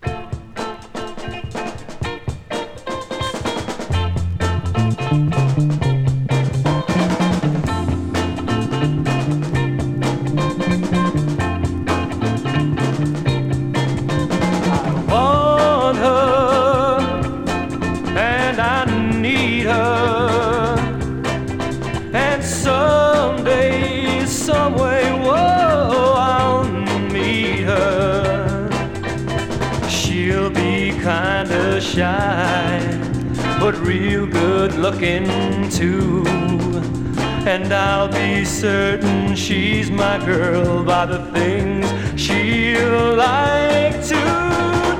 Rock, Pop　USA　12inchレコード　33rpm　Mono